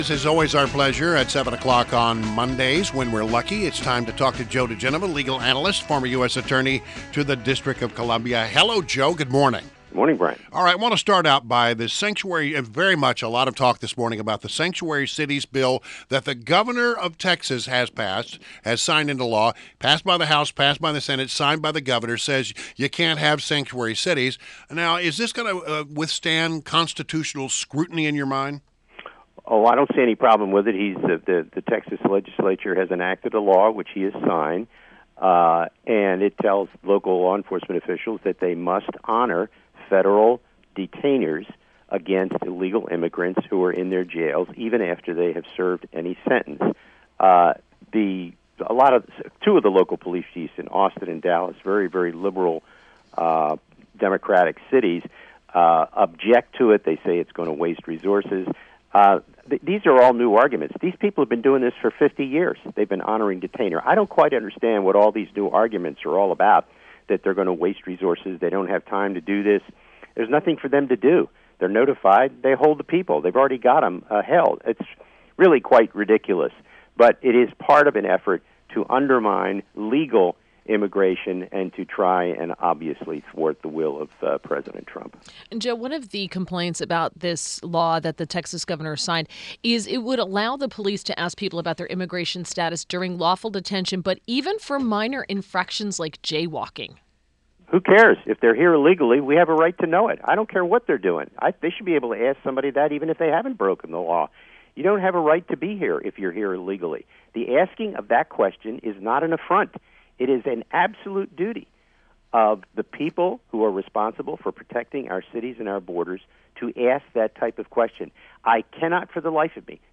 INTERVIEW — JOE DIGENOVA – legal analyst and former U.S. Attorney to the District of Columbia